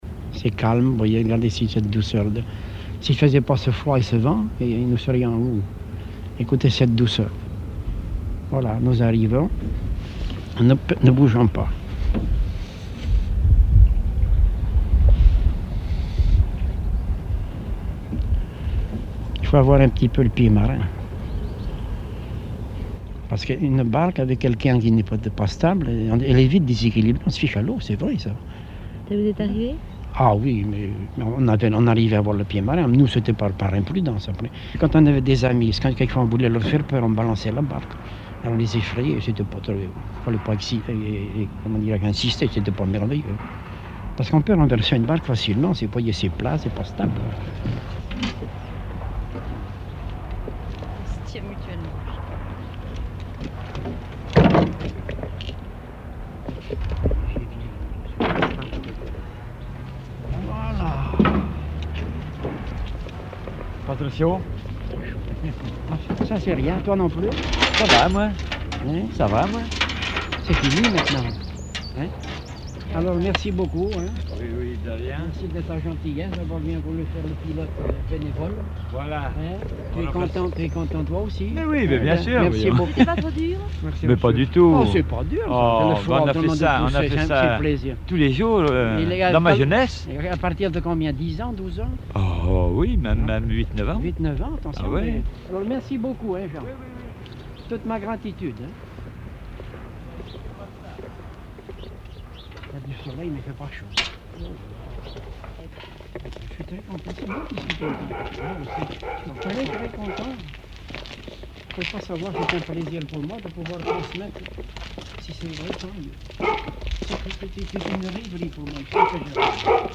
Emisson Départementale, sur Radio France Culture
Catégorie Témoignage